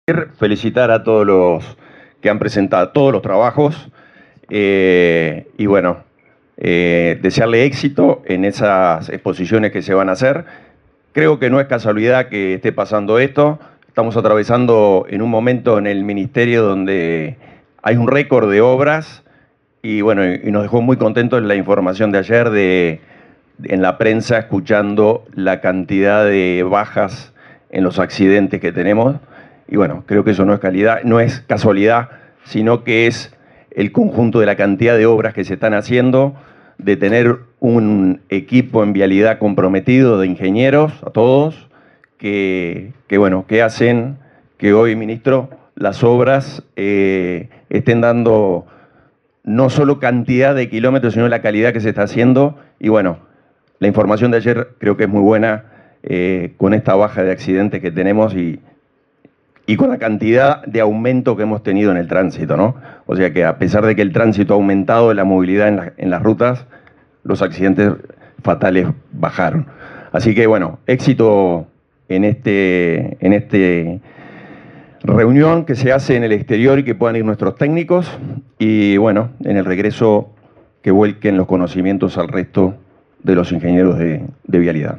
Palabras del director nacional de Vialidad, Hernán Ciganda
El director nacional de Vialidad, Hernán Ciganda, participó, este jueves 14 en Montevideo, en la exposición de proyectos para el XXII Congreso Ibero